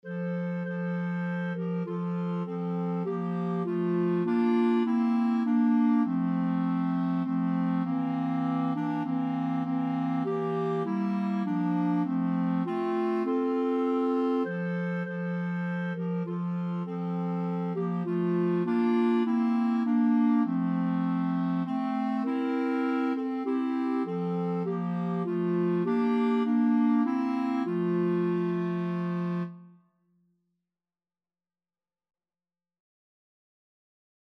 Clarinet Trio version
Christmas
3/4 (View more 3/4 Music)
Clarinet Trio  (View more Easy Clarinet Trio Music)